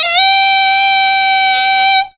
BLOW UP SAX.wav